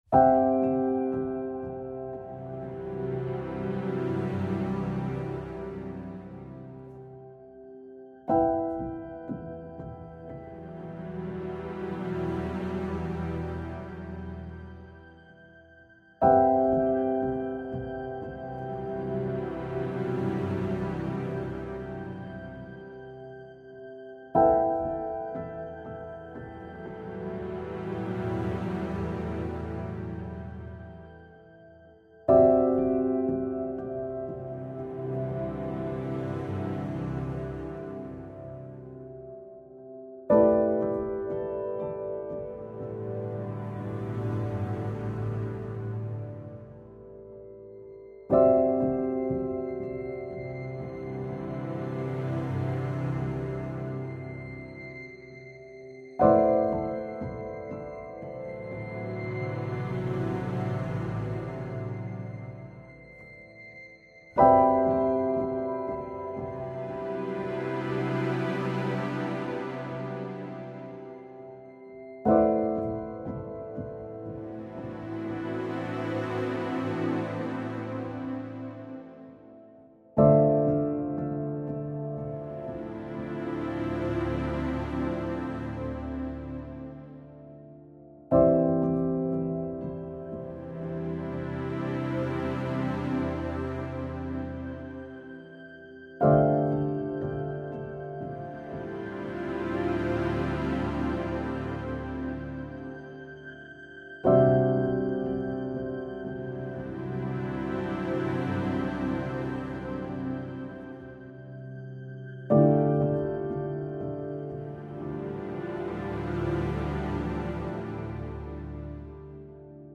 with strings